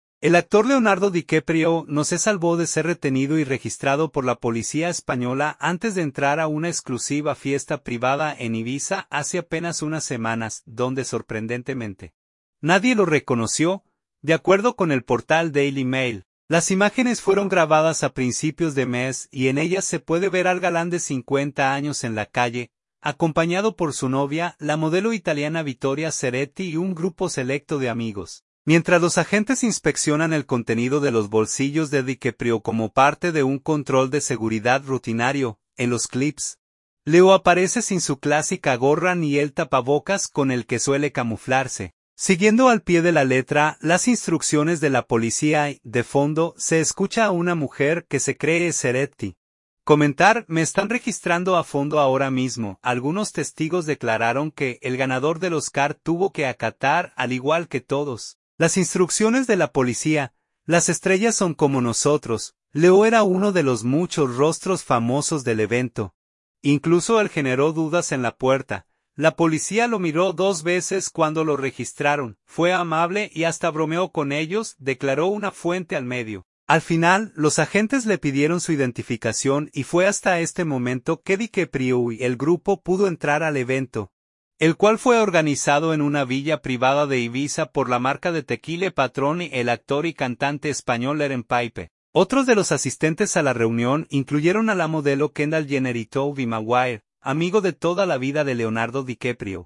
En los videos, se puede ver a DiCaprio en la calle, mientras los agentes inspeccionan el contenido de sus bolsillos como parte de un control de seguridad
En los clips, Leo aparece sin su clásica gorra ni el tapabocas con el que suele camuflarse, siguiendo al pie de la letra las instrucciones de la policía y, de fondo, se escucha a una mujer, que se cree es Ceretti, comentar: “Me están registrando a fondo ahora mismo”.